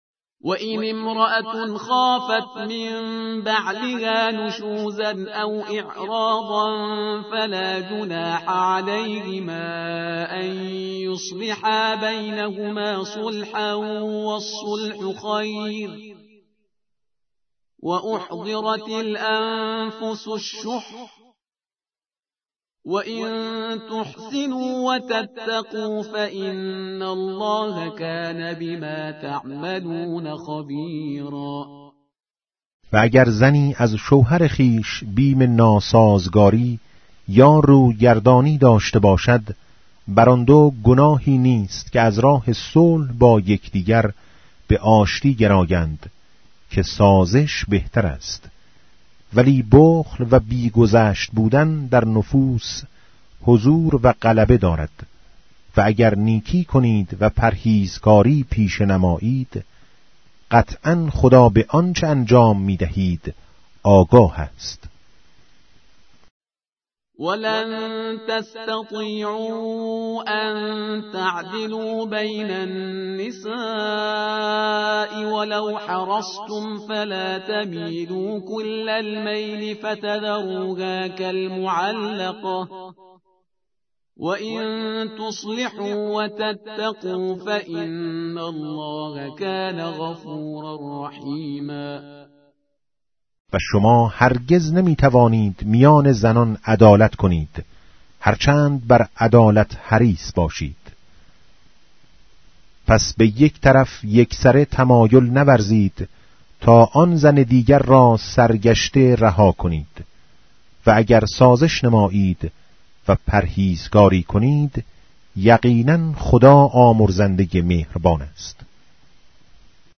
به گزارش گروه فرهنگی  پایگاه خبری تحلیلی «آوای سیدجمال»، از آنجا که پیامبر اکرم(ص)، در آخرین وصیت خود، از قرآن به‌عنوان ثقل اکبر یاد کرده و تأکید بر توجه به این سعادت بشری داشت، بر آن شدیم در بخشی با عنوان «کلام نور» تلاوتی از چراغ پرفروغ قرآن كه تلألو آن دل‌های زنگار گرفته و غفلت زده را طراوتی دوباره می‌بخشد به صورت روزانه تقدیم مخاطبان خوب و همیشه همراه آوای سیدجمال کنیم.